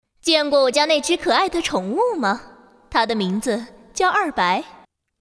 女声
少女萝莉-星座守卫-精灵魔女